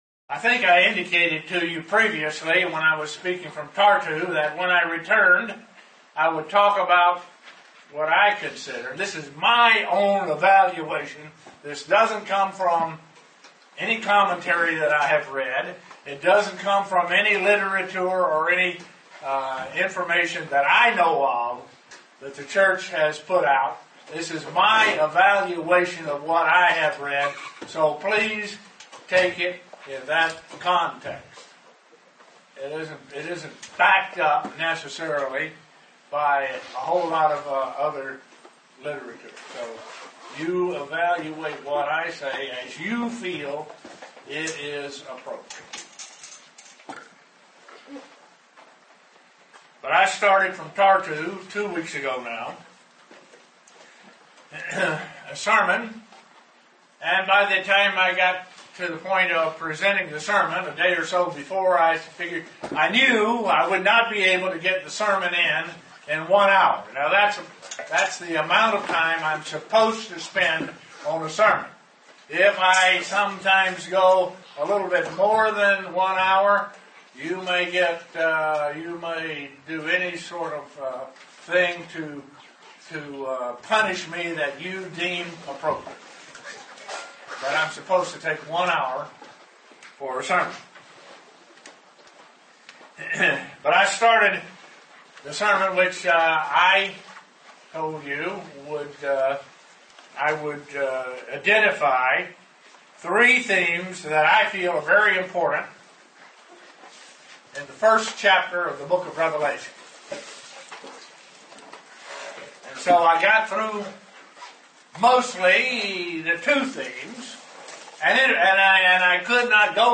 Given in Elmira, NY
Print What are three themes found in Rev 1 UCG Sermon Studying the bible?